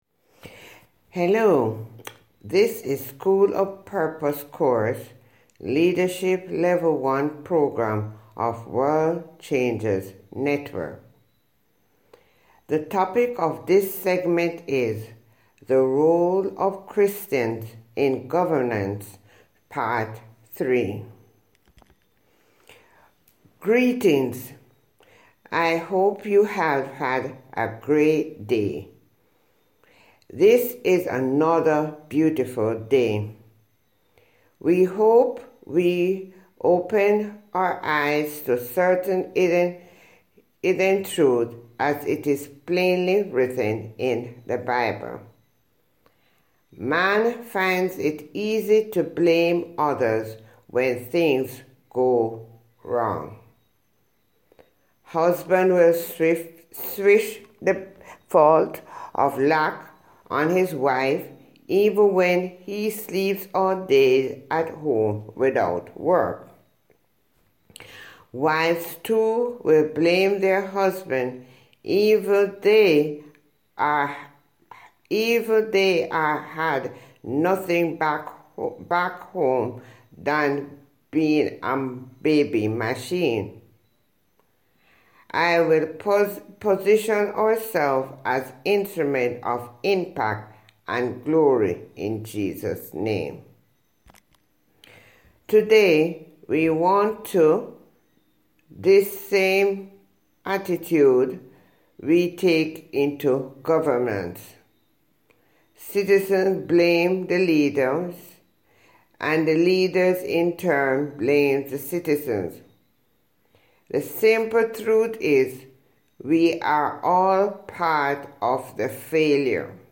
Listen to Natural Voice